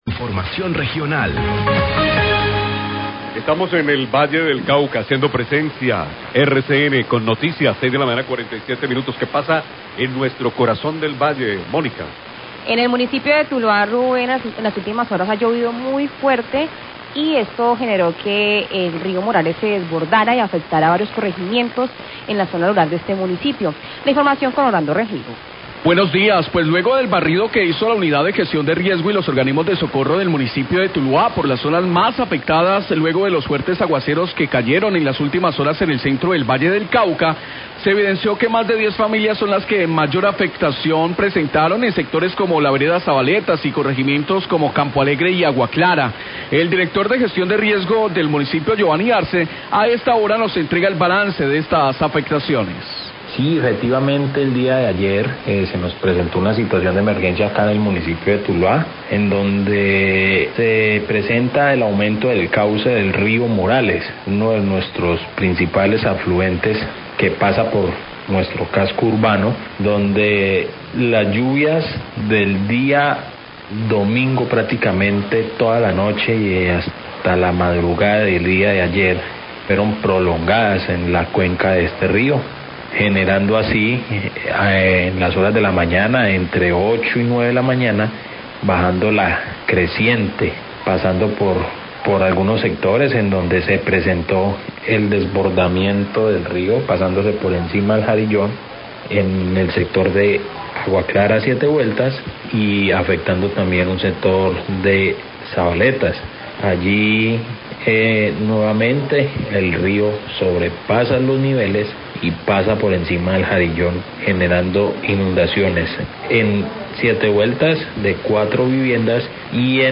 Radio
Las fuertes lluvias caídas en el día de ayer generaron el desbordamiento del Río Morales en zona rural de Tuluá afectando sectores de Zabaletas, Campoalegre y Agua Clara. El directo de la Oficina de Gestión del Riesgo de tuluá, Giovanny Arce, hace un balance de las afectaciones por las inundaciones.